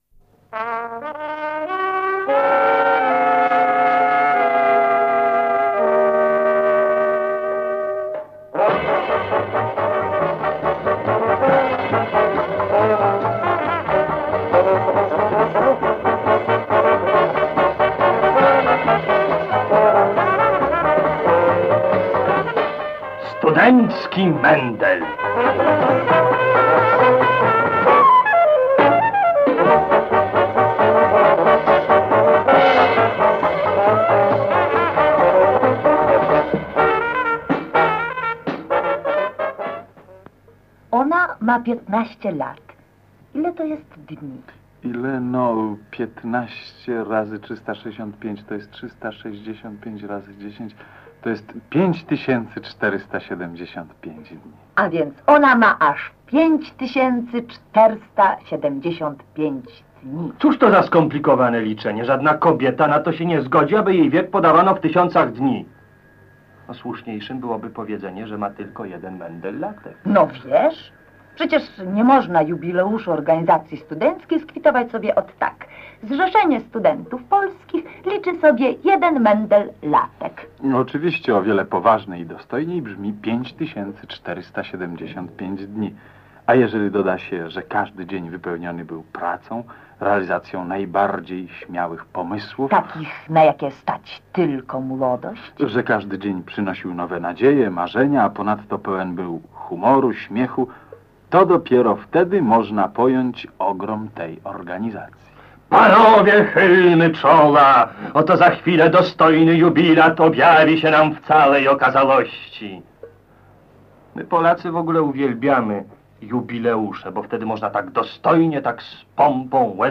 Audycja o kulturze studenckiej lat 60-tych w perspektywie dorobku XV-lecia ZSP